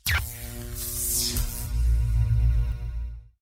shield.ogg